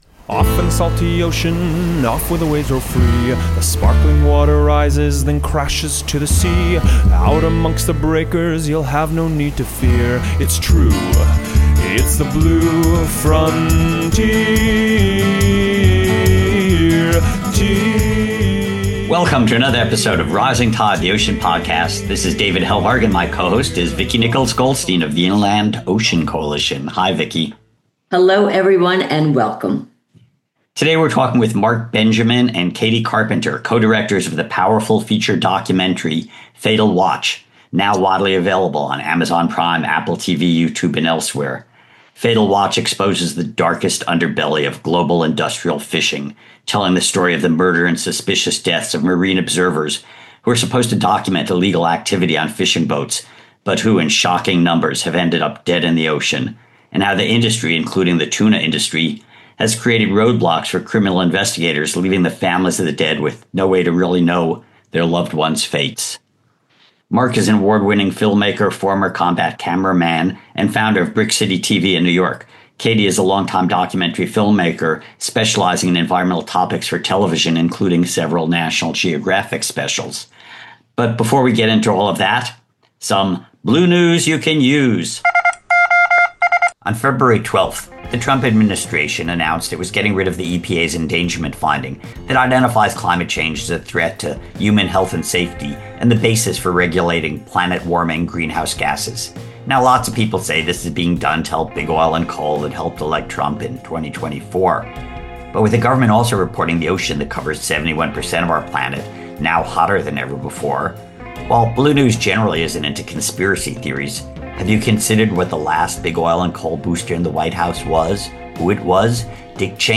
After listening to this wide-ranging and revealing conversation, audiences will undoubtedly want to experience the film for themselves.